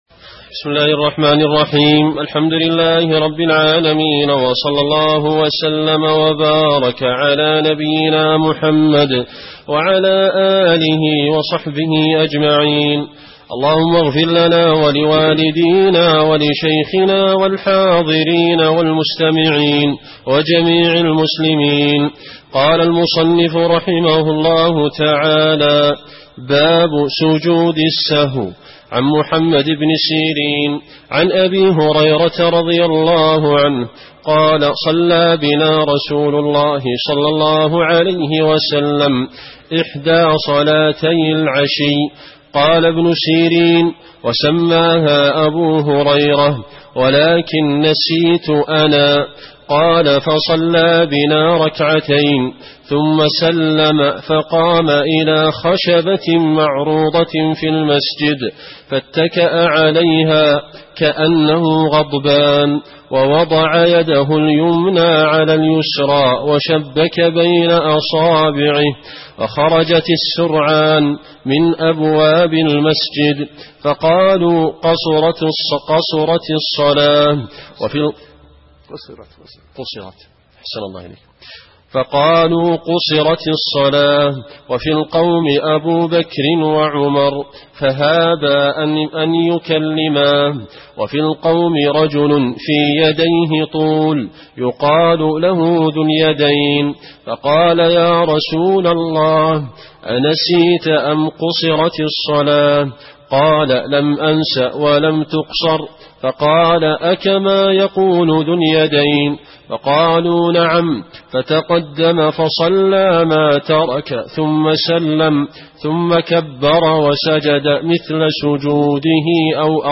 الدرس الثاني عشر من بداية باب سجود السهو إلى نهاية حديث أنس بن مالك (قال: كنا نصلي مع رسول الله في شدة الحر)